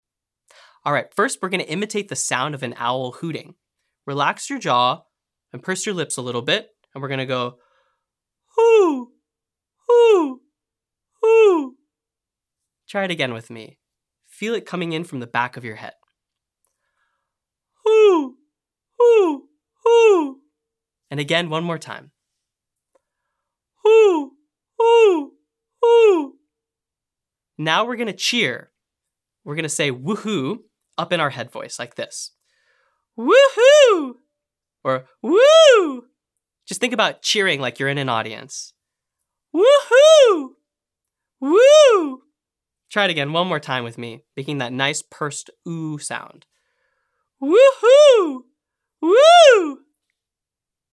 • Owl Hoot/woohoo cheer imitation to access your head voice.
• SOVT Lip Trill or straw phonation (1-8-1) to warm up head voice using a partially occluded space.
• Siren slide on "Wee" to stretch and develop falsetto range.